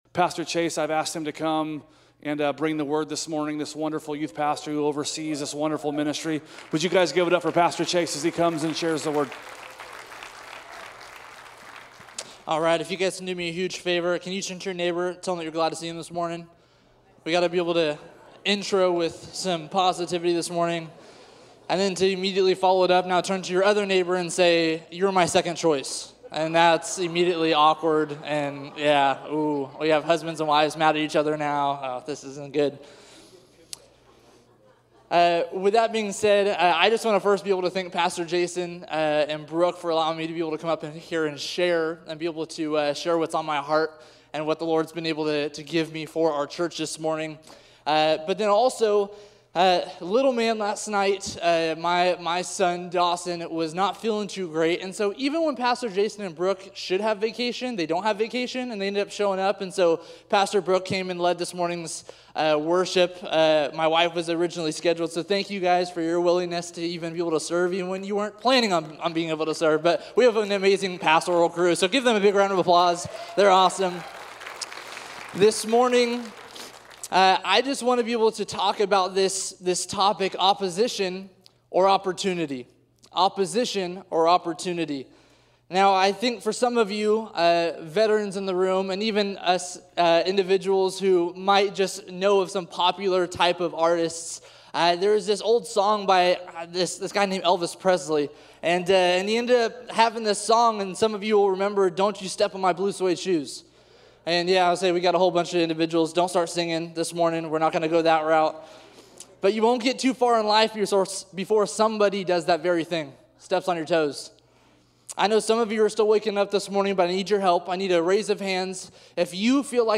A message from the series "Sunday Specials."